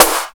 16 SNARE.wav